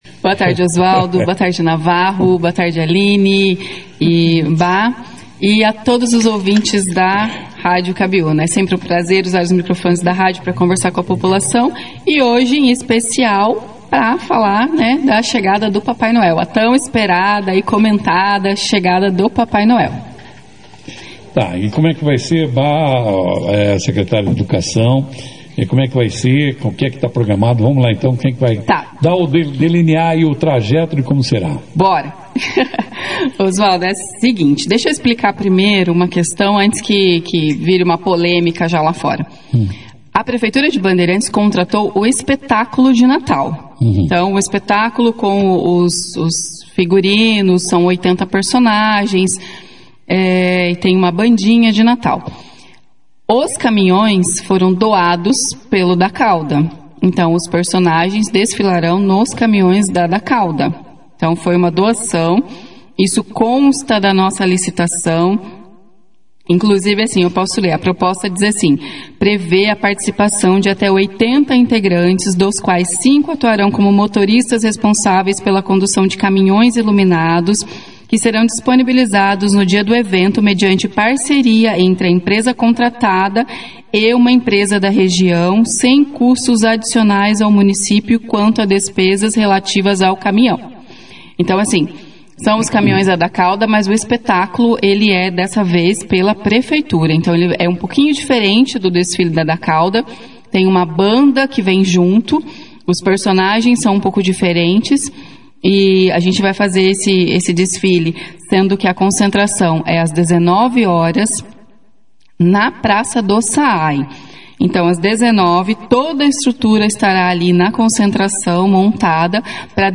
As secretárias de Administração de Bandeirantes, Cláudia Janz, da Educação, professora Aline Firmino das Neves Vasconcelos, e o diretor de Cultura, Everton Bonfim Romano (Bhá), participaram da 2ª edição do Jornal Operação Cidade, nesta terça-feira, dia 16 de dezembro.